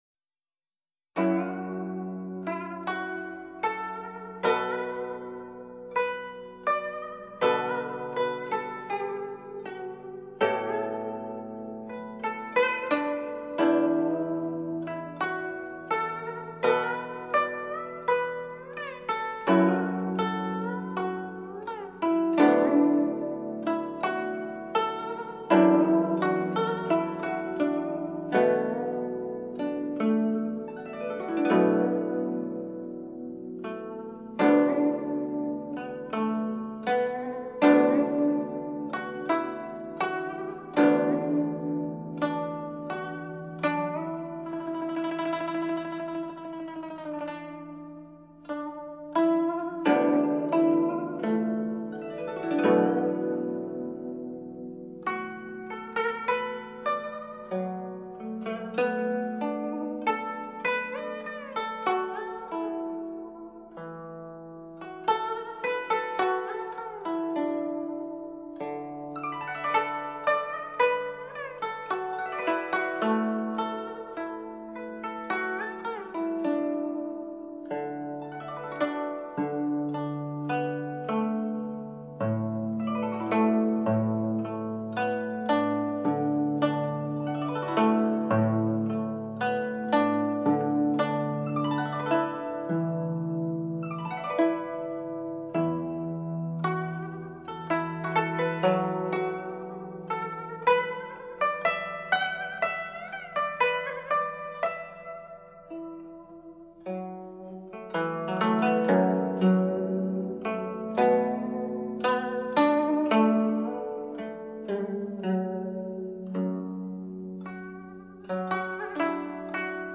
CD 5 [ 弹拨乐器（2）]
古筝独奏